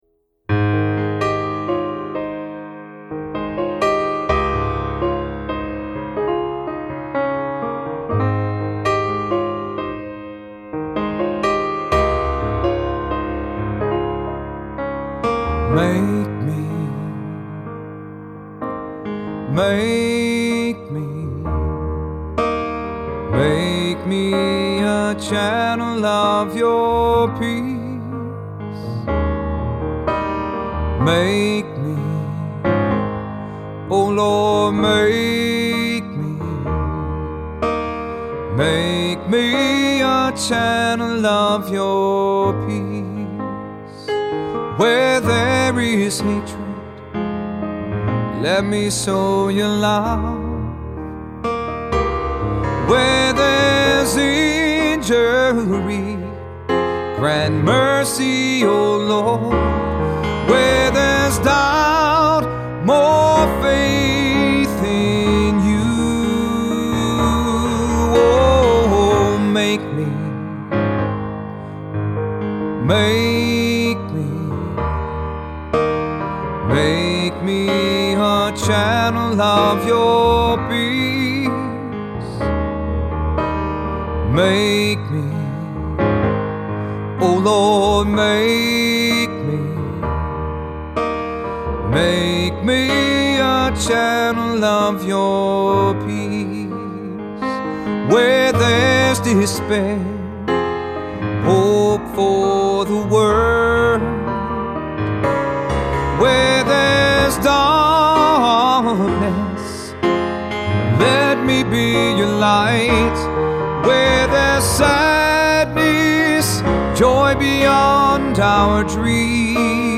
Voicing: SAT; Cantor; Assembly